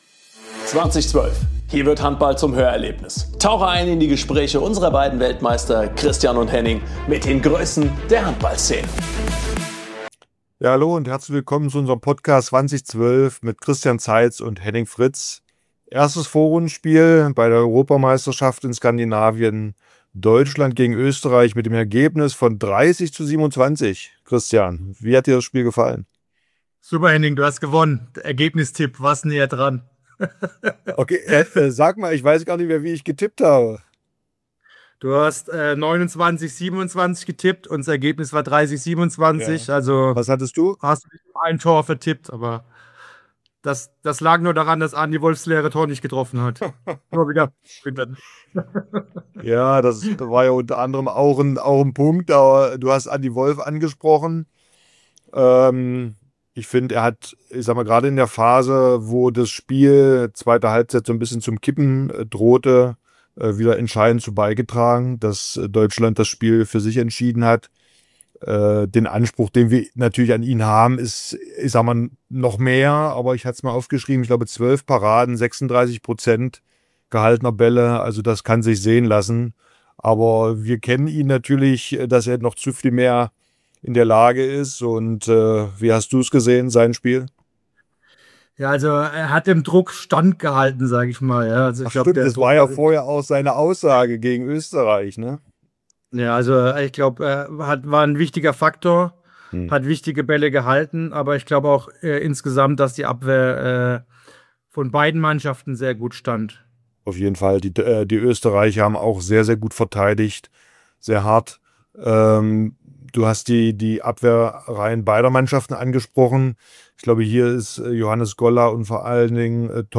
Beschreibung vor 2 Monaten Zwei Weltmeister, zwei Meinungen – so schaut echter Handball-Real Talk aus.